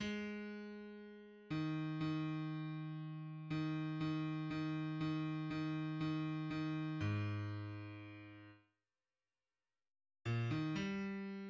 {\clef bass \tempo 4 = 120 \key ees \major \time 4/2 aes2. ees4 ees2. ees4\time 3/2 ees4 ees ees ees ees ees \time 4/2 aes,2. r2 r4 r8 bes,8 ees8 g4. }\midi{}